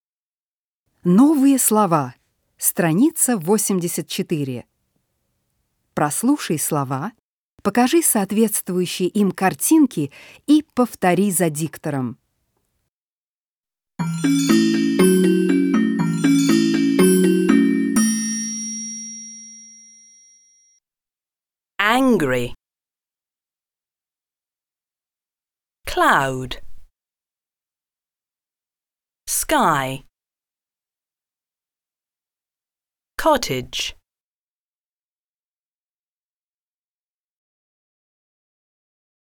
Прослушай слова, покажи соответствующие им картинки и повтори слова за диктором.